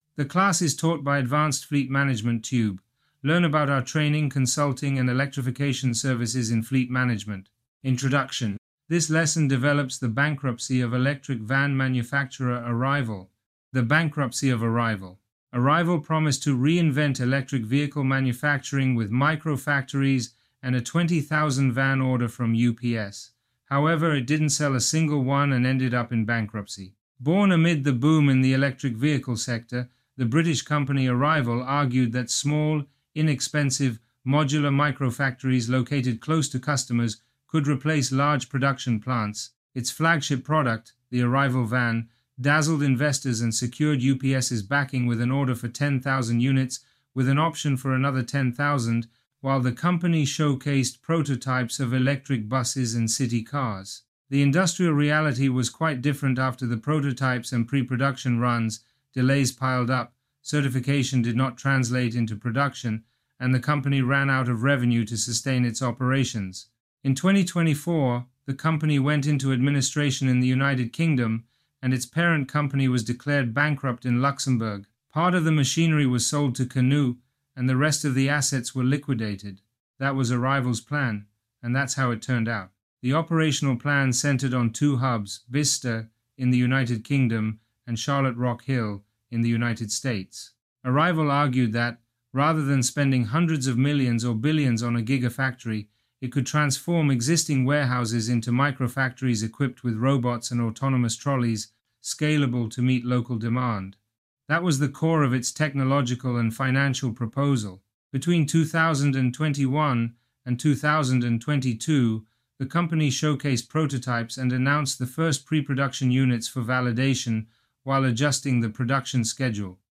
Online class